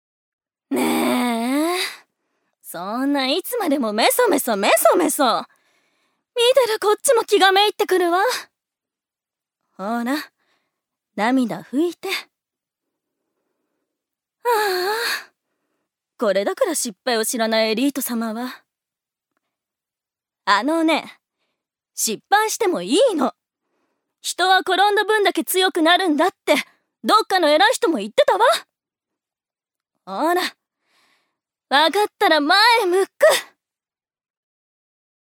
預かり：女性
セリフ３